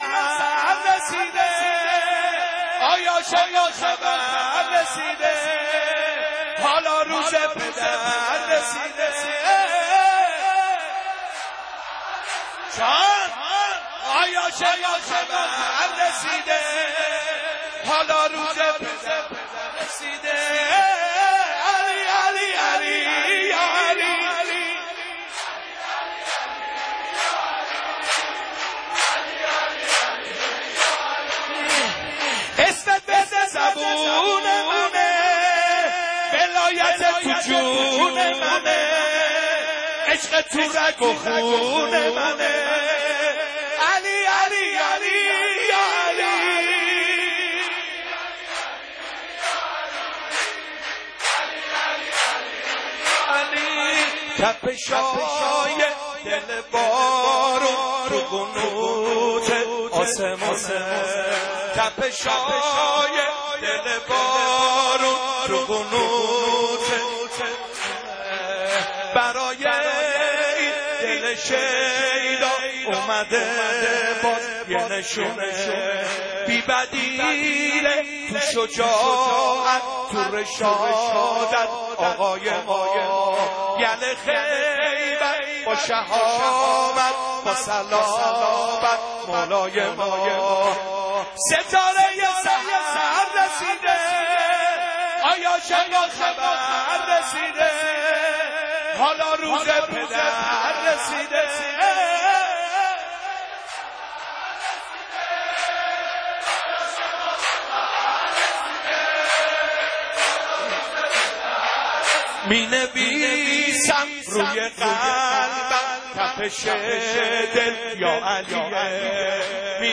مولودی | آهای عاشقا خبر رسیده حالا روز پدر رسیده